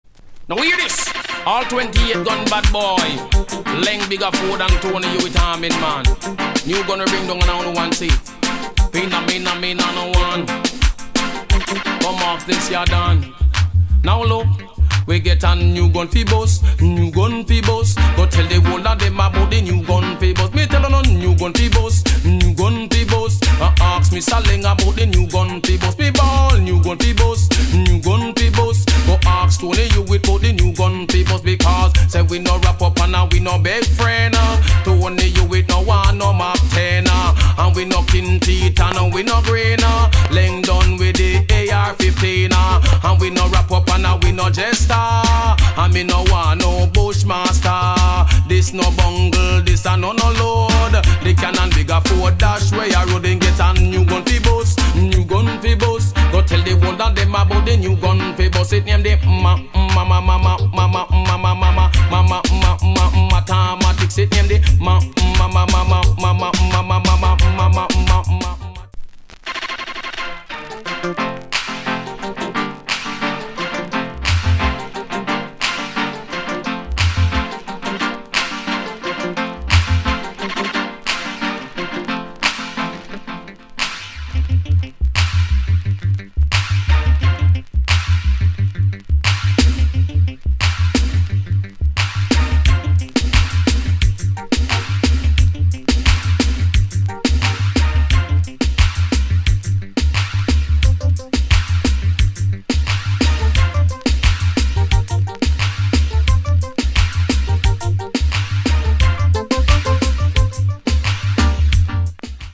*'89 Big Bad Rude Boy Tune.